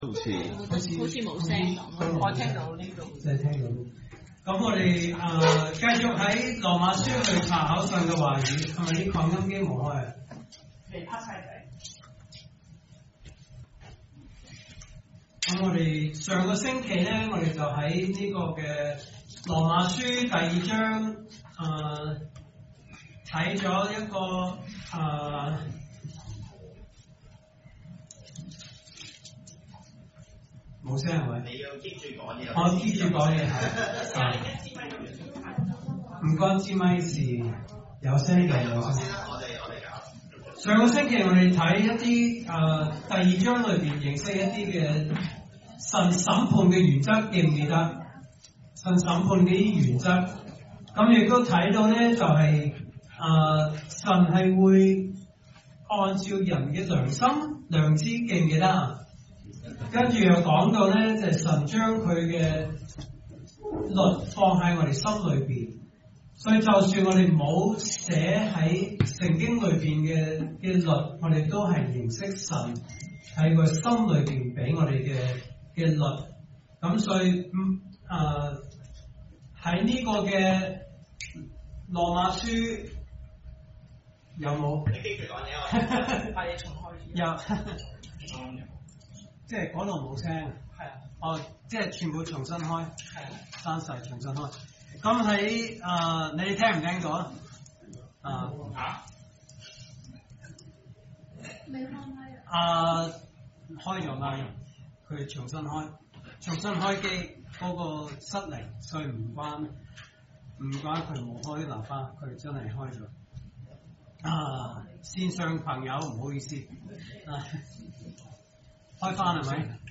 來自講道系列 "查經班：羅馬書"